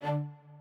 strings6_58.ogg